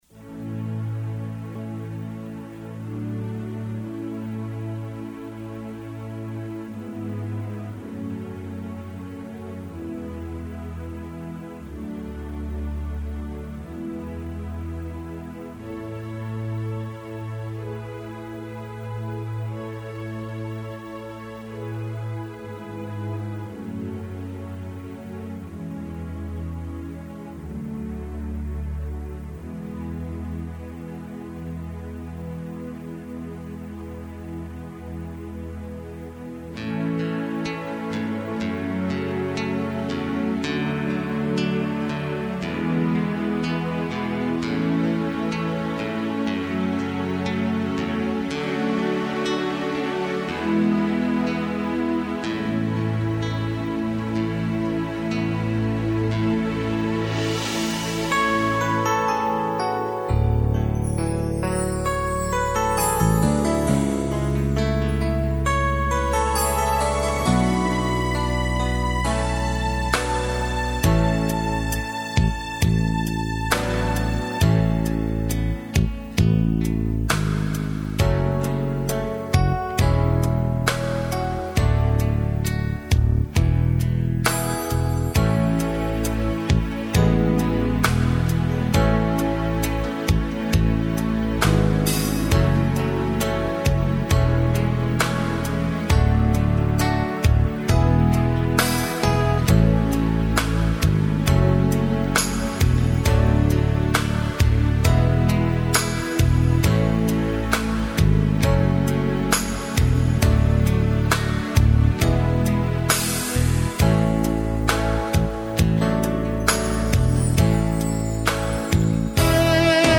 Тональность: A, H